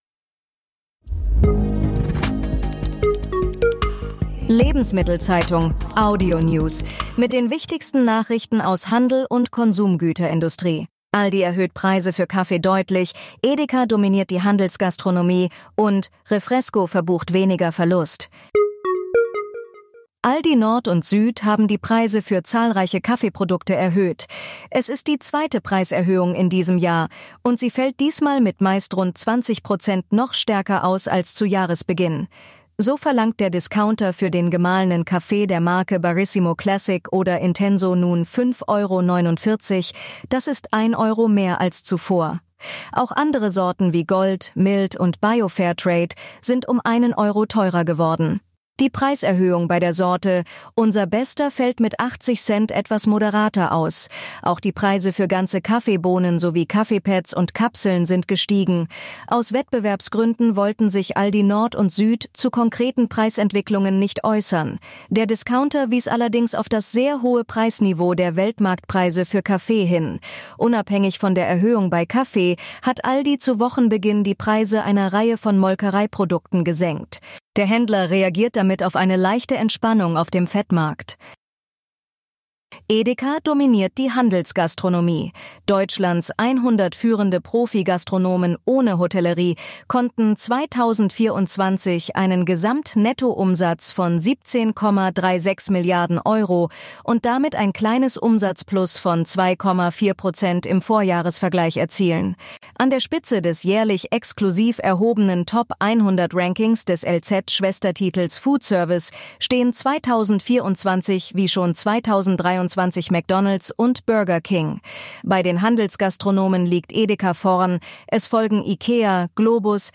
Tägliche Nachrichten